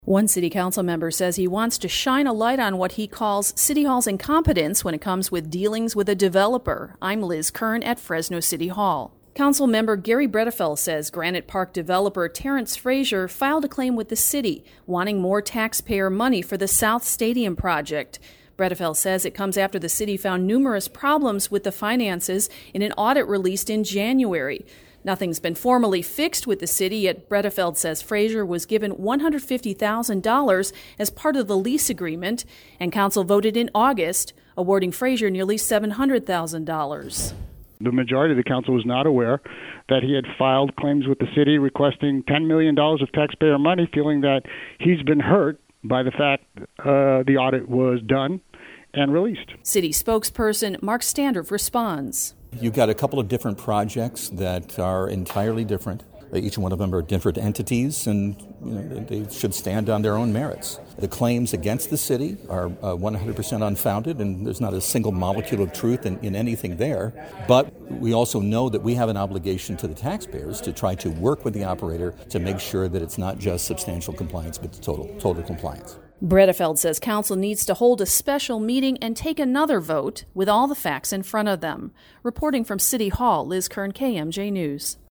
At a press conference held Tuesday at Fresno City Hall, Bredefeld told media it comes after the City found numerous problems with the finances in an audit released in January of 2019.